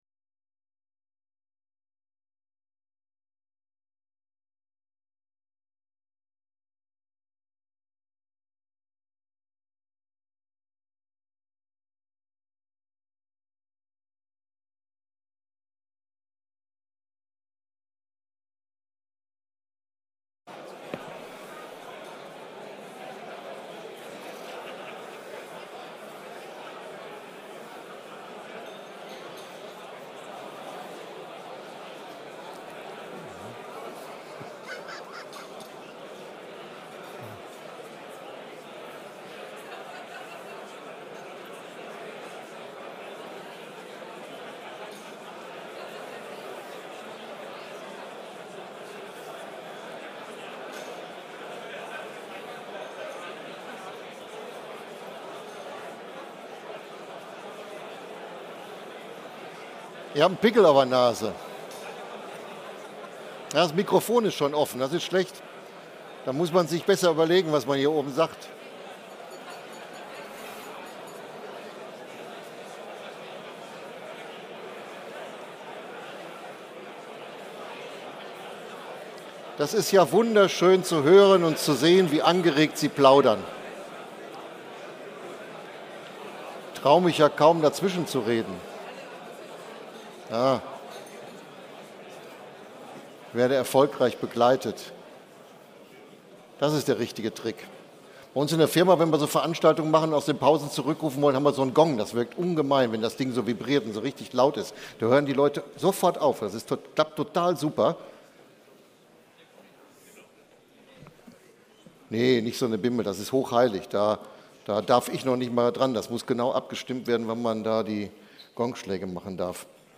Reinoldimahl 2023 mit Festrednerin Frau Dr. Strack-Zimmermann